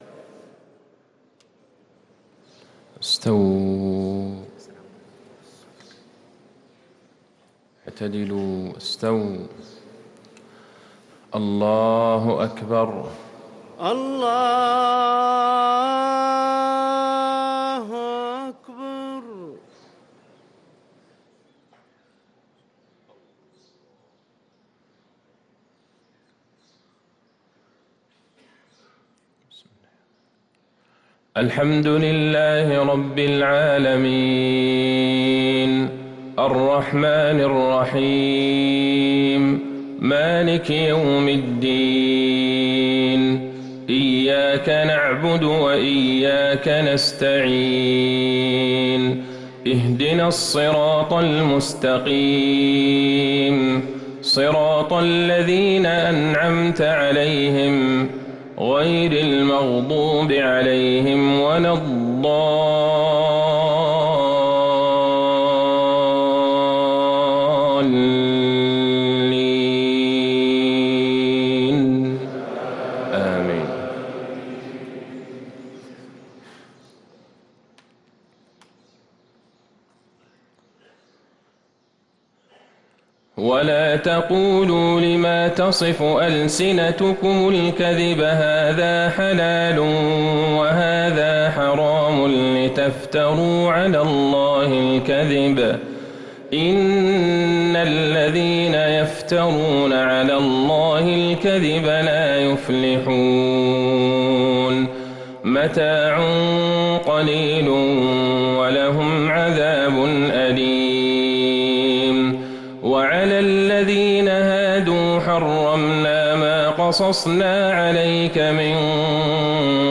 صلاة الفجر للقارئ عبدالله البعيجان 6 رمضان 1444 هـ